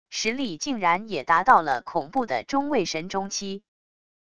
实力竟然也达到了恐怖的中位神中期wav音频生成系统WAV Audio Player